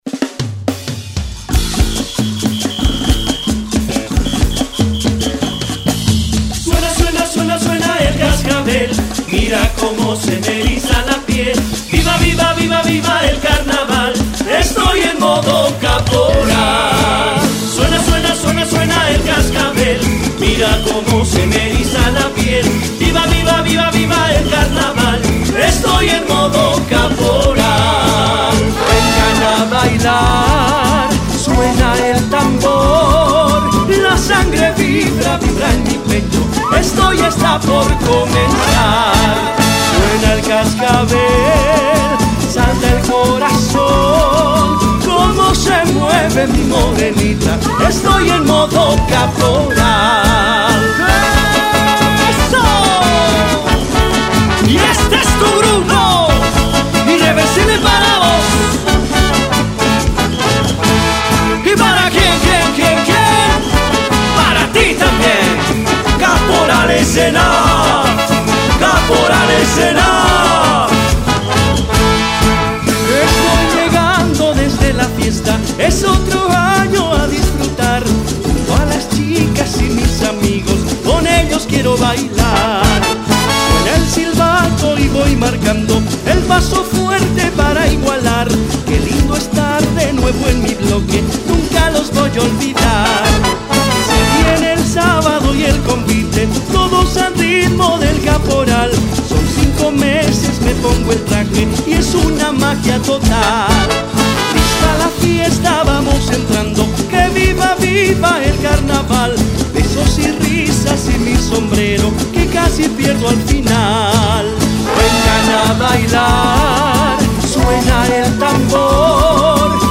Genre Caporal